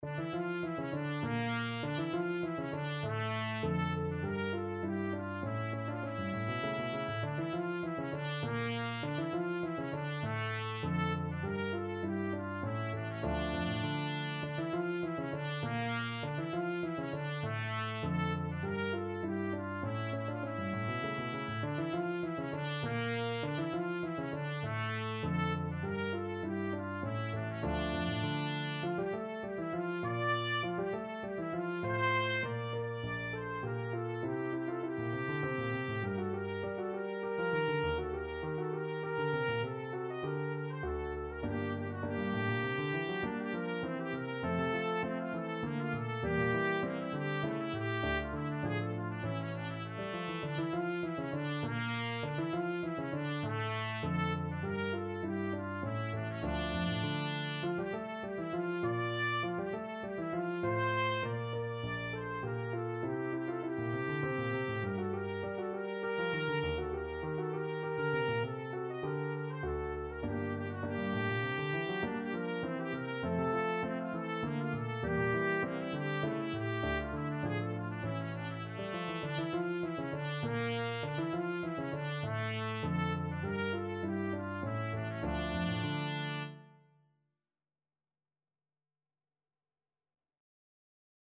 3/4 (View more 3/4 Music)
Allegretto = 100
Classical (View more Classical Trumpet Music)